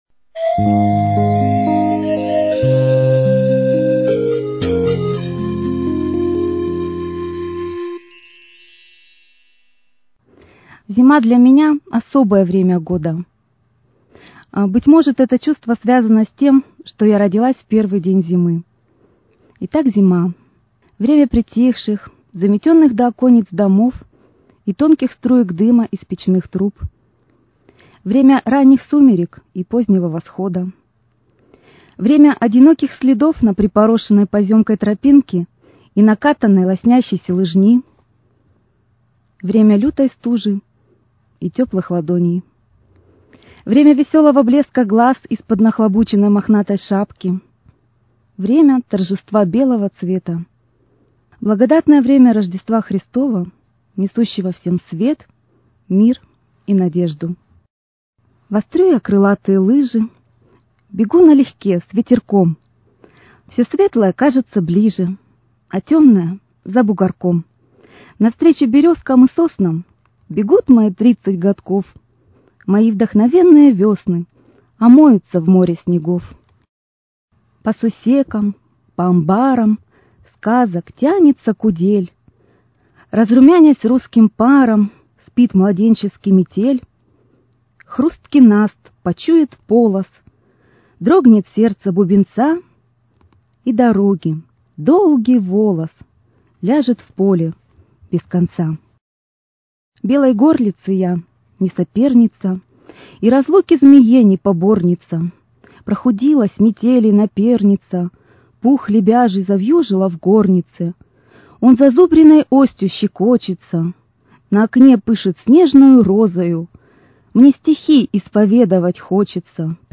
Читаем автор.